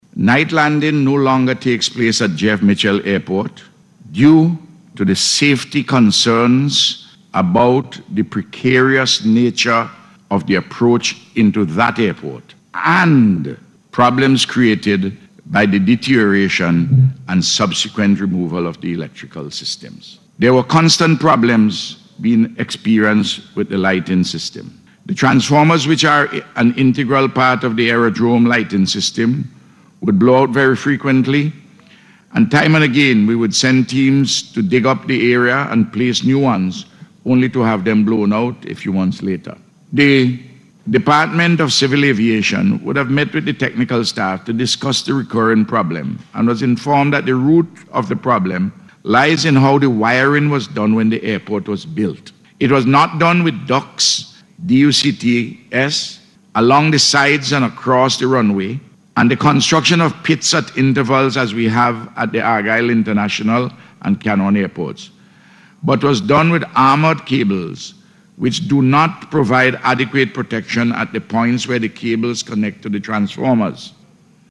This was disclosed by Prime Minister Dr. Ralph Gonsalves, as he responded to a question in Parliament on Thursday from Leader of the Opposition Dr. Godwin Friday, in relation to night landing at the Airport.